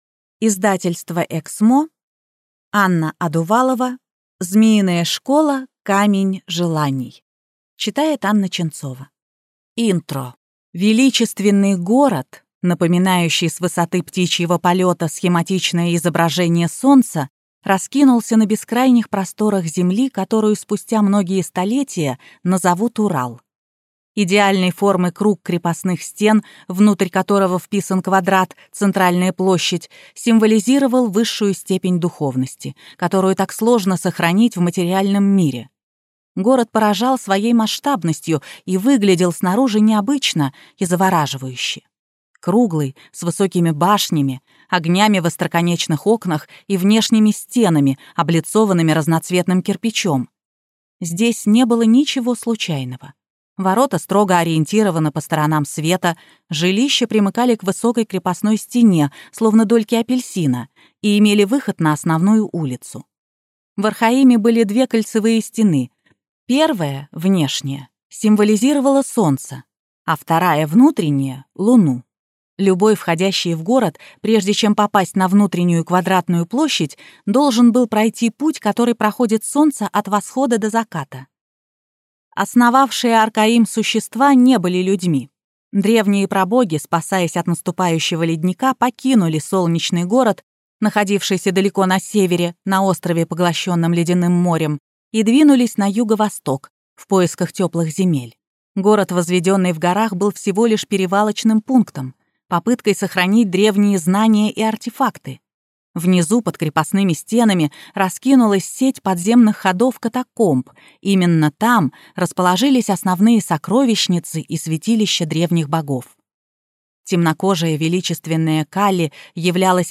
Аудиокнига Камень желаний | Библиотека аудиокниг